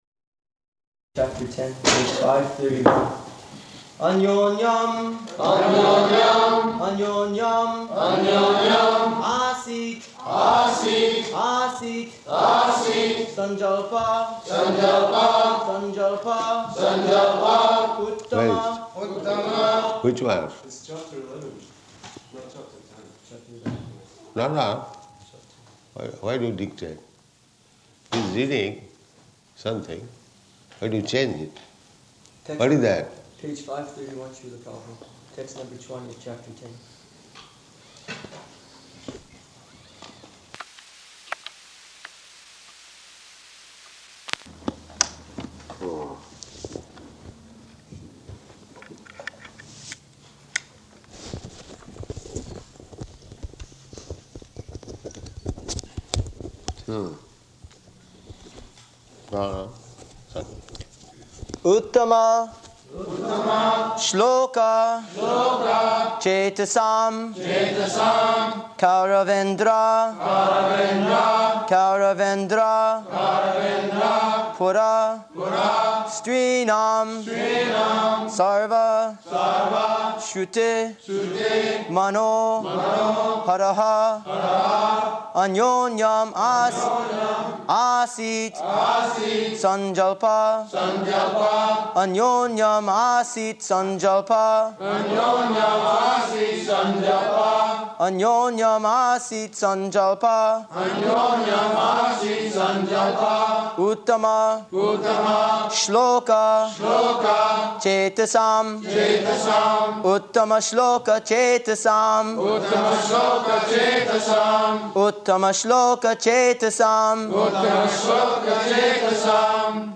Śrīmad-Bhāgavatam 1.10.20 --:-- --:-- Type: Srimad-Bhagavatam Dated: May 24th 1973 Location: London Audio file: 730524SB.LON.mp3 Devotee: Chapter Ten, page 531. anyonyam āsīt sañjalpa uttama...